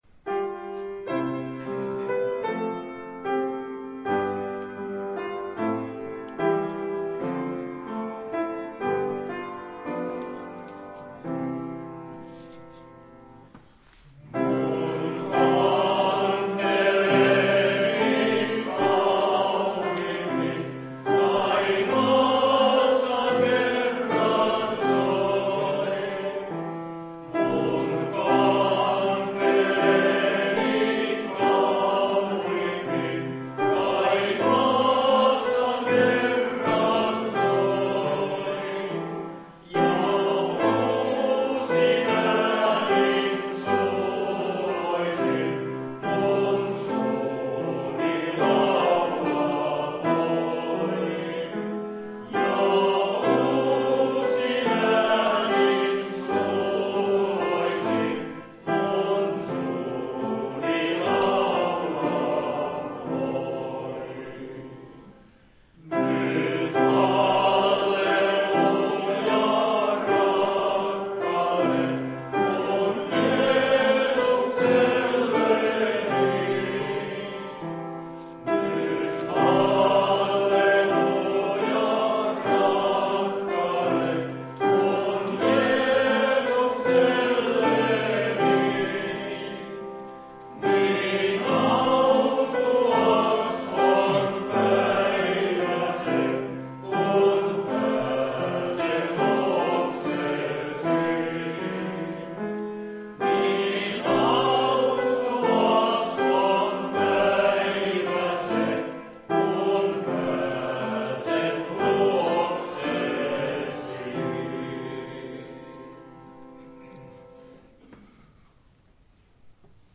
säestys